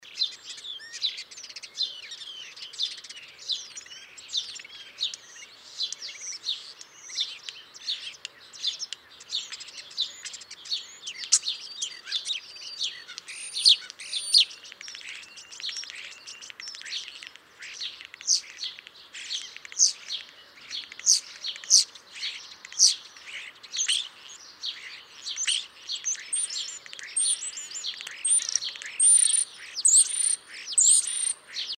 На этой странице собраны разнообразные звуки скворцов: от мелодичного пения до характерного свиста.
Звук скворца в гнезде